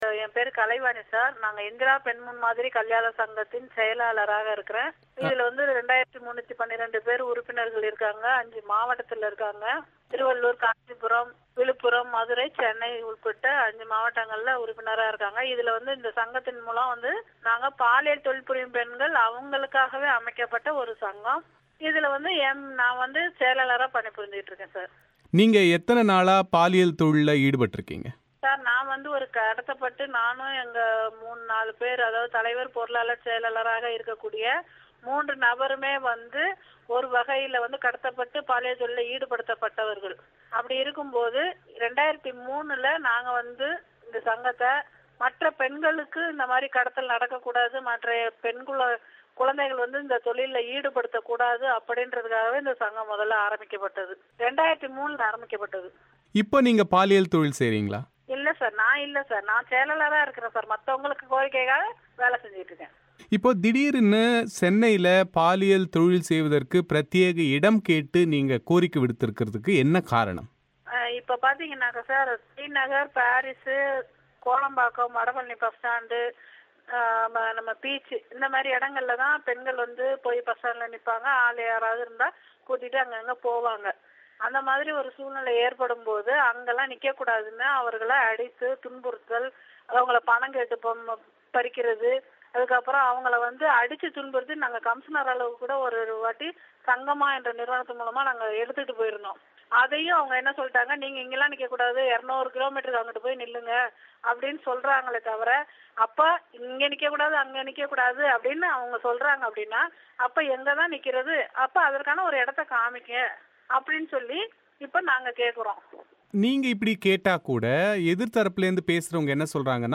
பிபிசி தமிழோசைக்கு அளித்த பேட்டியில் இந்த கோரிக்கைக்கான நியாயங்களை விளக்கினார்.